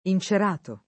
incerato [ in © er # to ]